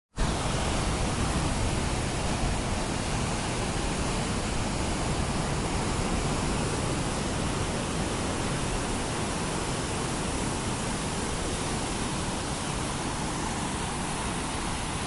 audio-sound-west-coast-new-zealand-coastline.mp3